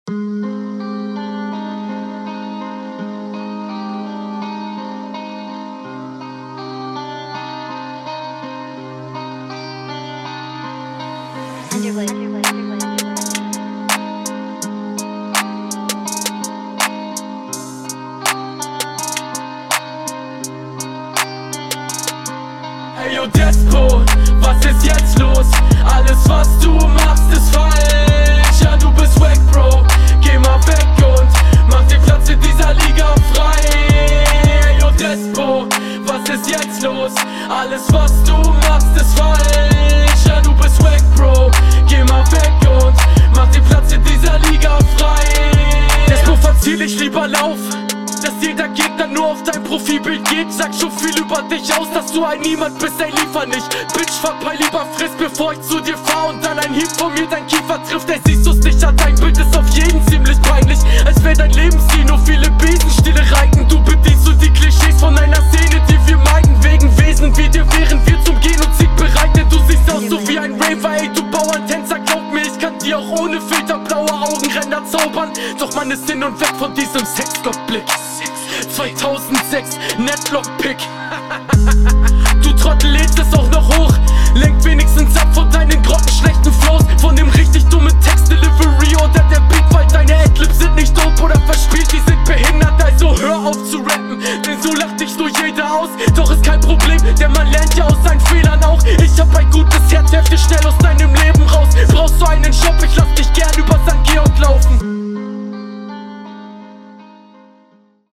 coole hook, kommt mir melodisch sehr bekannt vor aber klingt richtig nice. bist nicht immer …